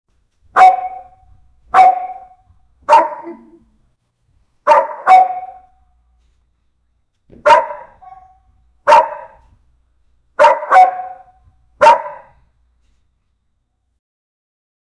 dog-barking-sound-for-kid-rjqb7my4.wav